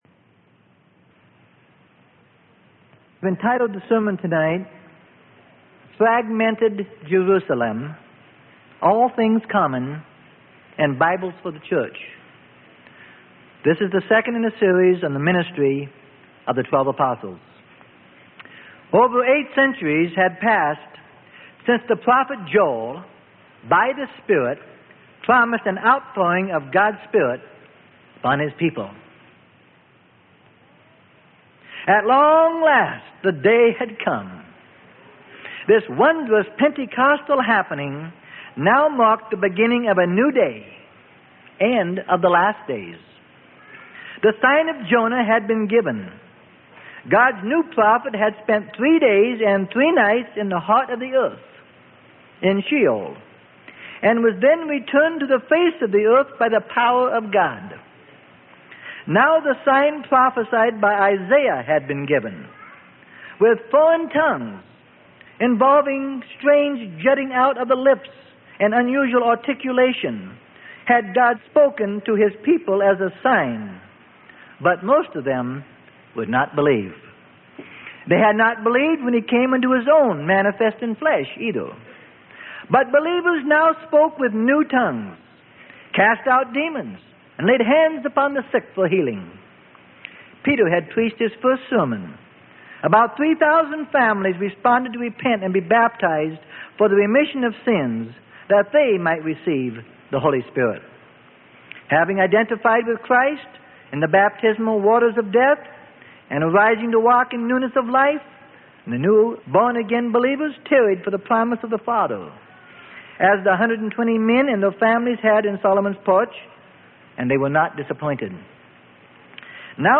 Sermon: The Acts Of The Apostles - Part 02 Of 13.